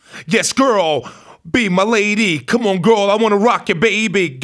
RAPHRASE06.wav